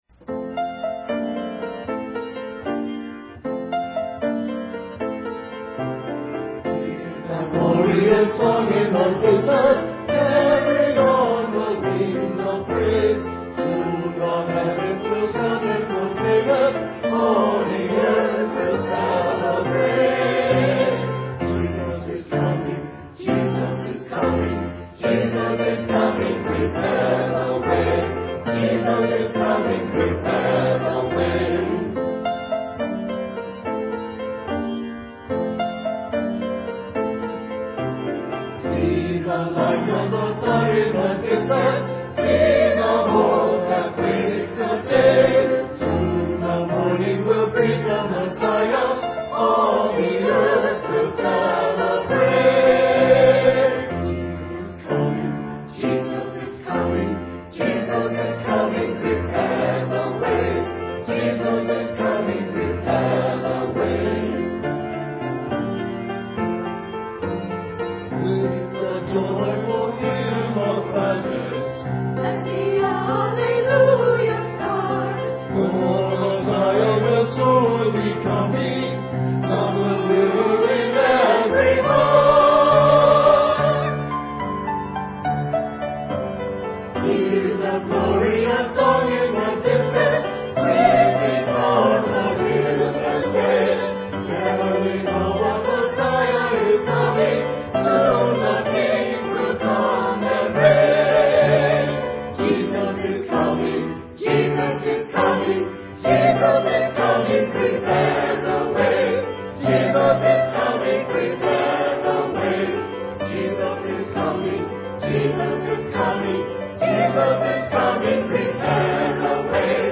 Sunday Service
Christmas Cantata